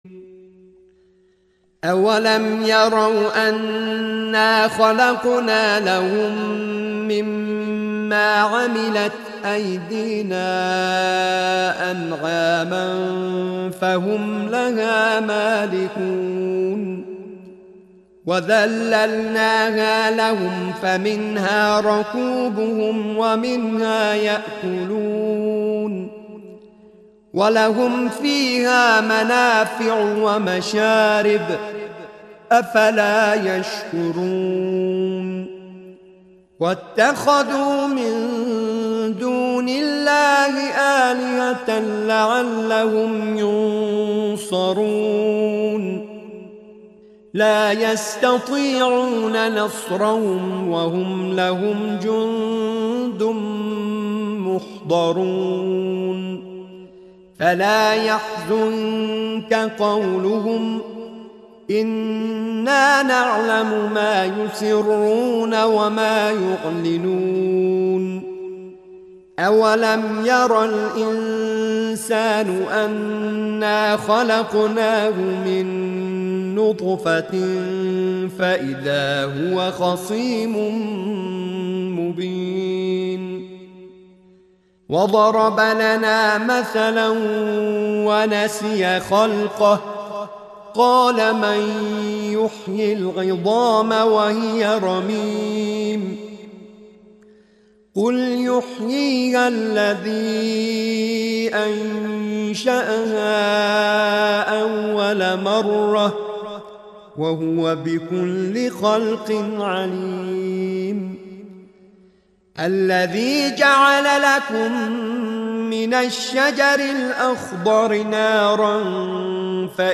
سوره مبارکه یس آیات 71 تا 83/ نام دستگاه موسیقی: نهاوند